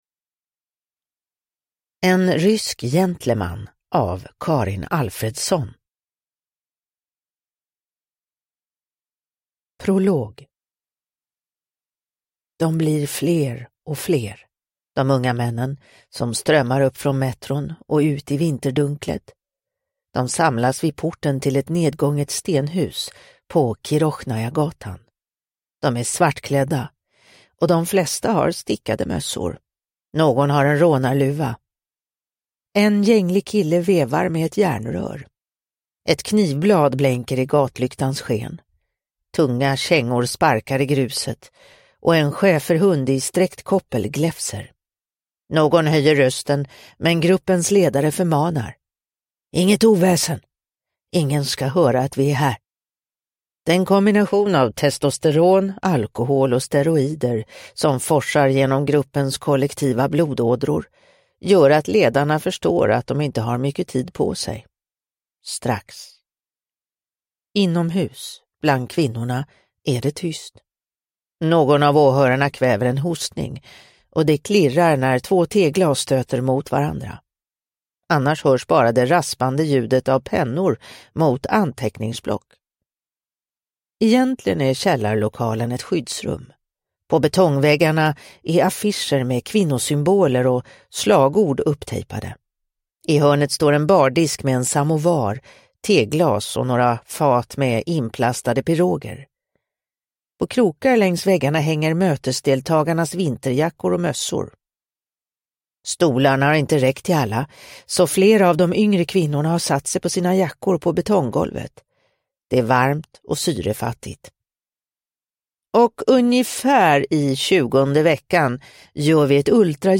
En rysk gentleman – Ljudbok – Laddas ner